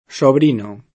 Sobrino [ S obr & no ]